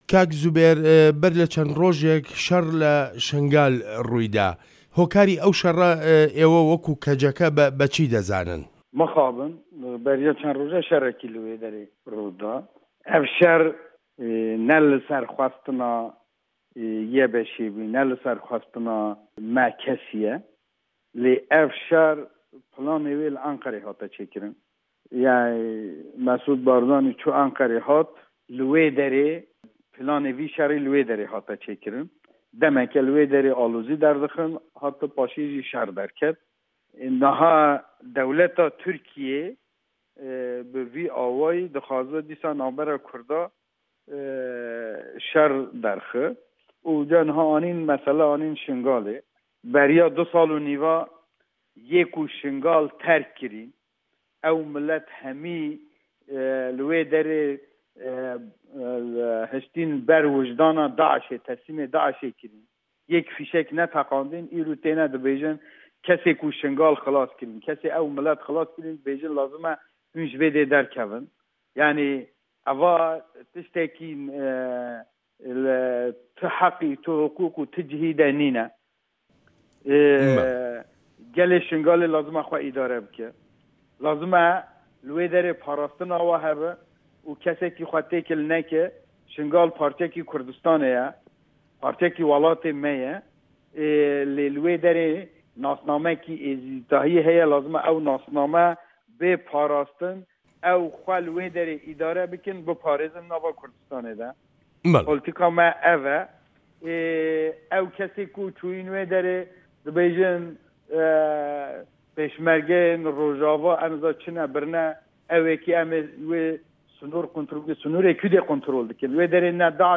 Hevpeyvîn li gel Zûbêr Aydar Endamê Konseya Koma Civakên Kurdistan "KCK"ê